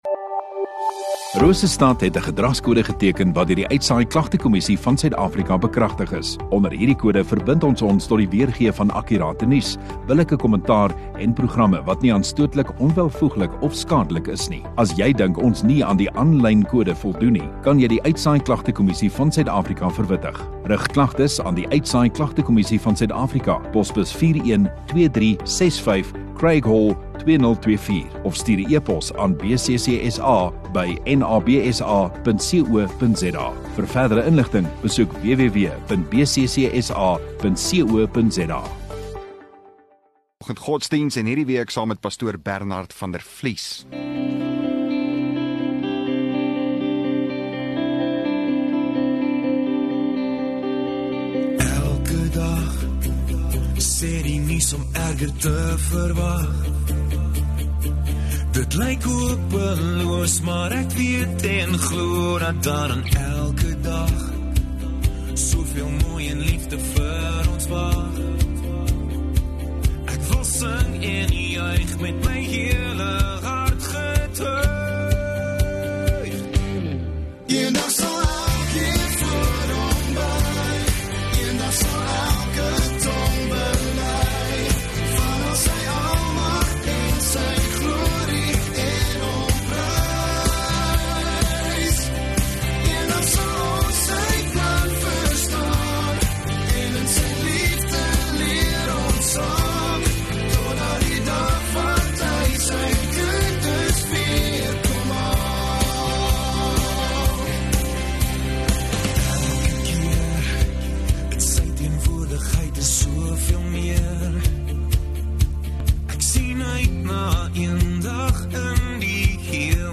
19 Jun Donderdag Oggenddiens